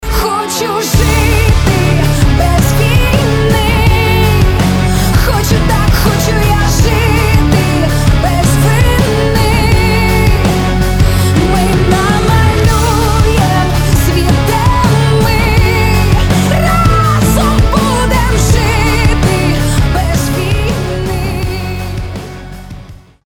• Качество: 320, Stereo
сильные